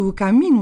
u camminu [ k ] : sourde